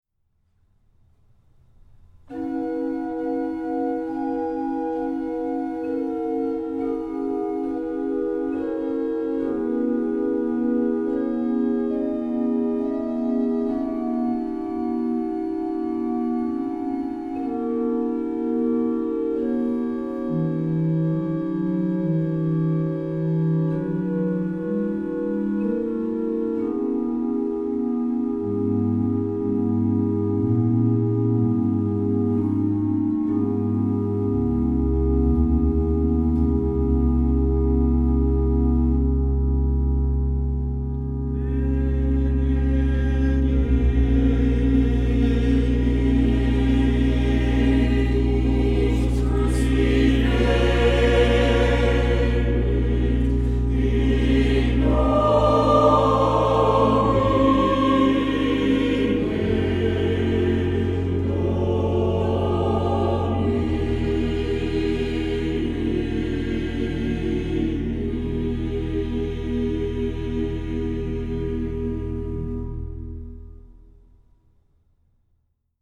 Genre-Style-Form: Mass
Mood of the piece: lively
Type of Choir: SATB  (4 mixed voices )
Instrumentation: Organ  (1 instrumental part(s))
Tonality: E major